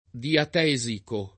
diatesico [ diat $@ iko ] → diatetico